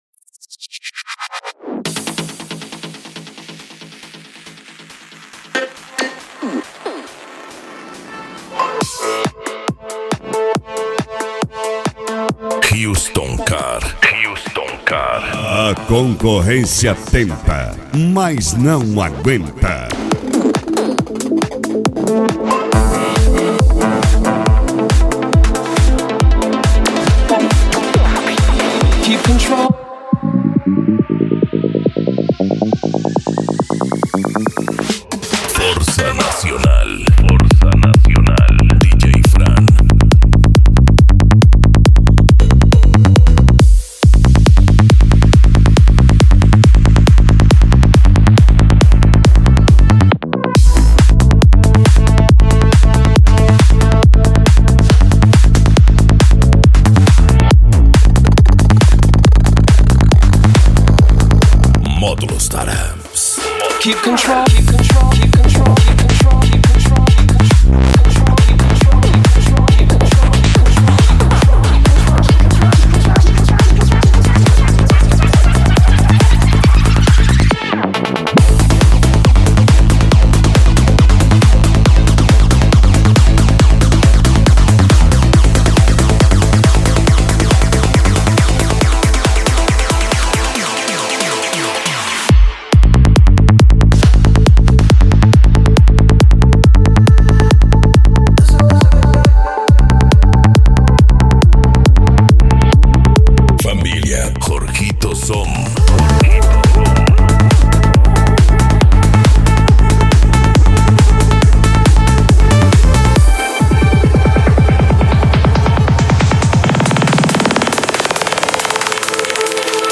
Bass
Cumbia
Funk
Musica Electronica
Remix